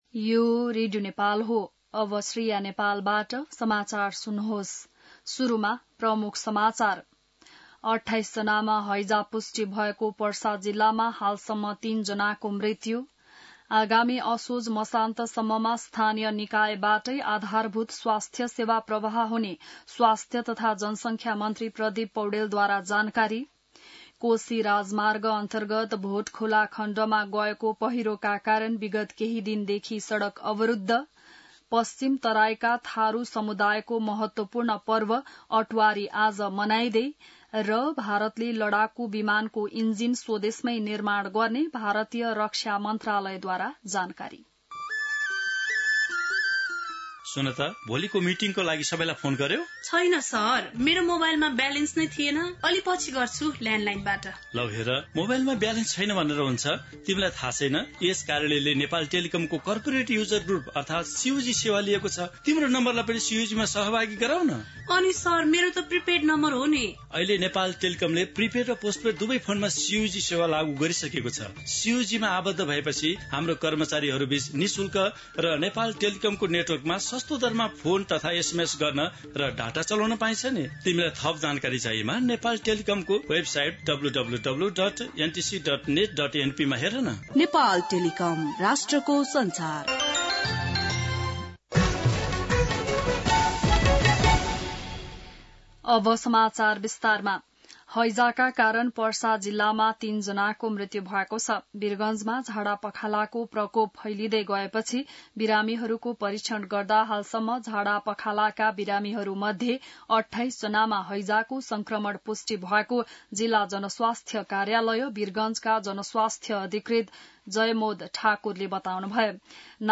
An online outlet of Nepal's national radio broadcaster
बिहान ७ बजेको नेपाली समाचार : ८ भदौ , २०८२